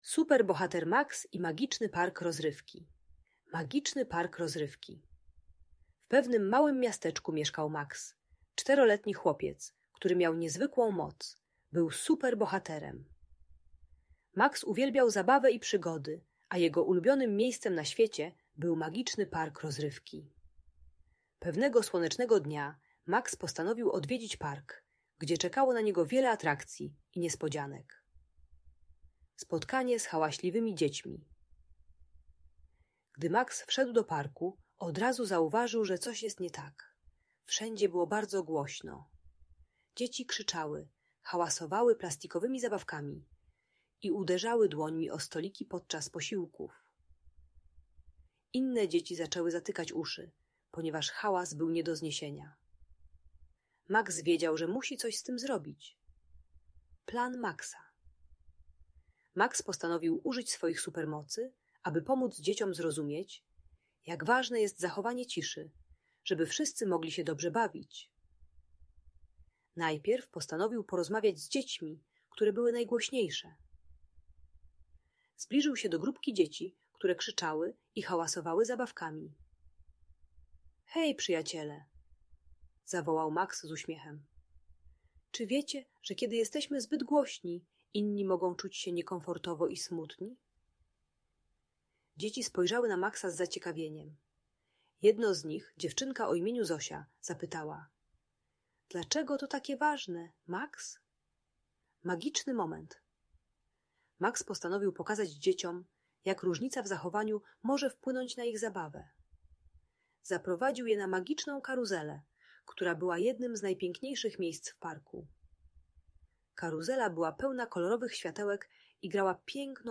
Super Bohater Max i Magiczny Park Rozrywki - - Audiobajka